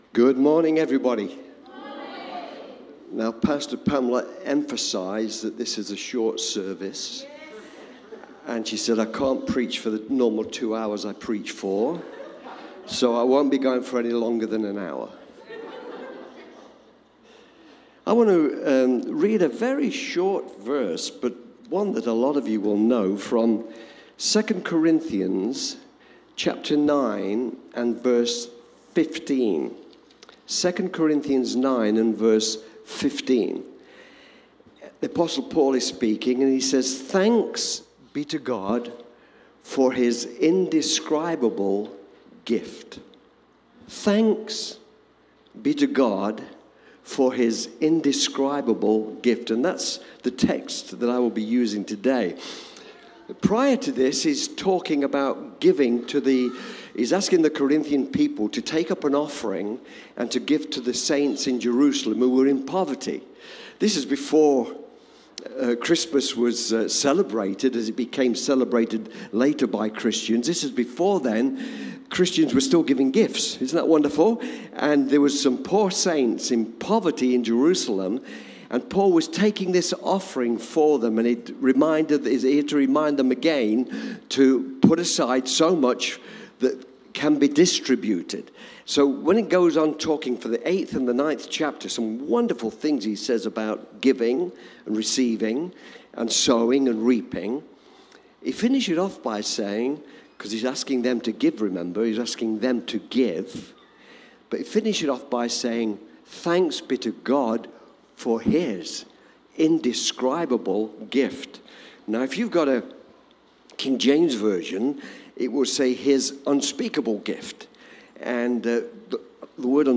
Boxing Day Service - Cityview Church